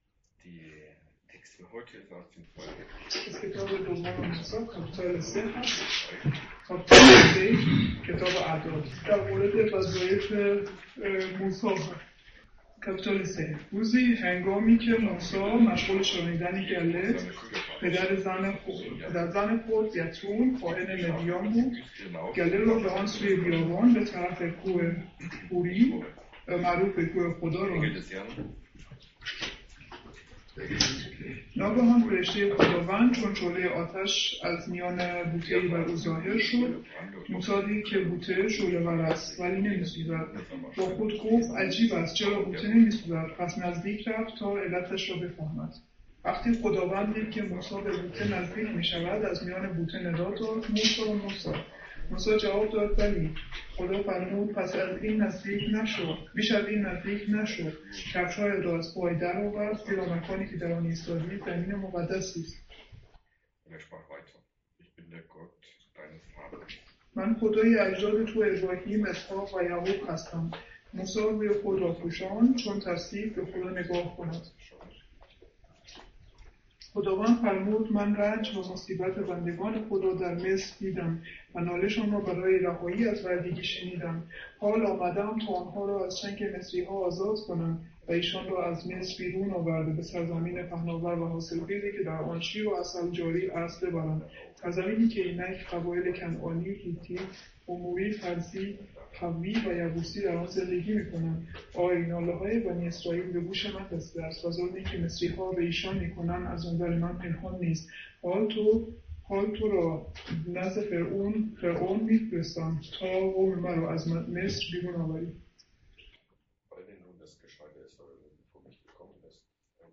Mose 3,1-13| zum Thema: Berufung| Übersetzung in Farsi